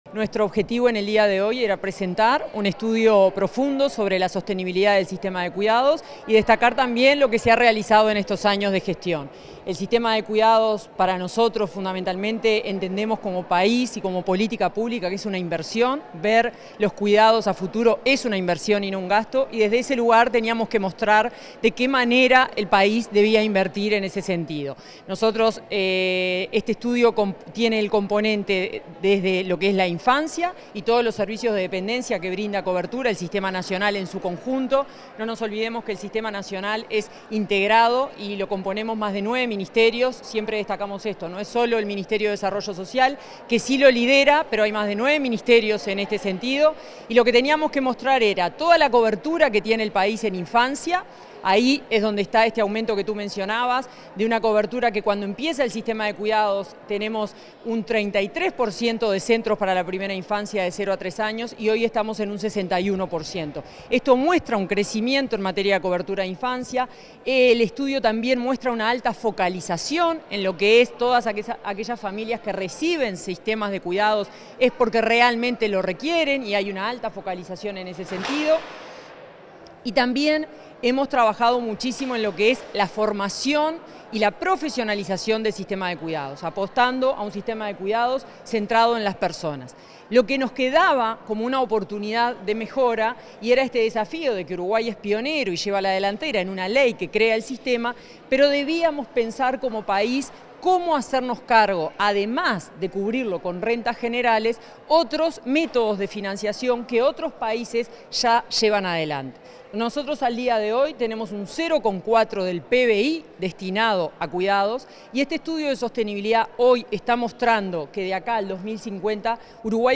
Declaraciones de la directora de Cuidados del Mides, Florencia Krall
Declaraciones de la directora de Cuidados del Mides, Florencia Krall 28/01/2025 Compartir Facebook X Copiar enlace WhatsApp LinkedIn Tras la presentación de un estudio de sostenibilidad, este 28 de enero, la directora de Cuidados del Ministerio de Desarrollo Social (Mides), Florencia Krall, realizó declaraciones a la prensa.